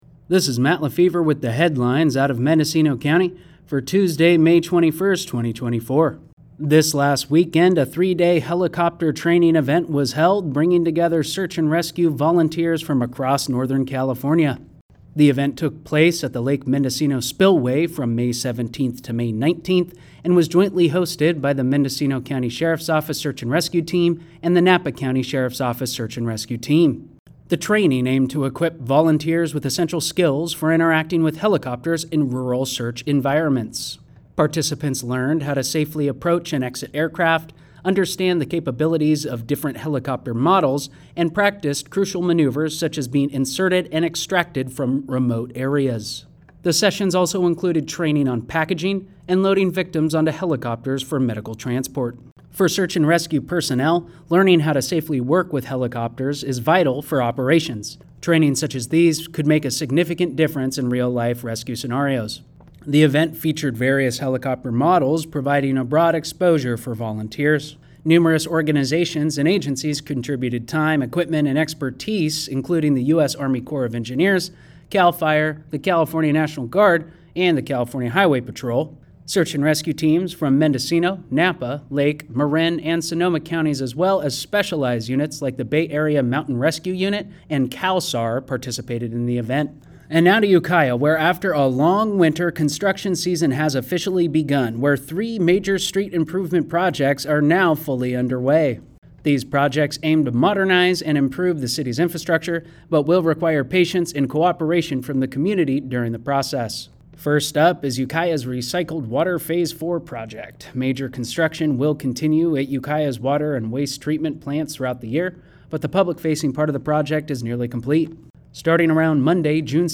brings today's headlines from Mendocino County